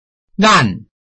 臺灣客語拼音學習網-客語聽讀拼-饒平腔-鼻尾韻
拼音查詢：【饒平腔】lan ~請點選不同聲調拼音聽聽看!(例字漢字部分屬參考性質)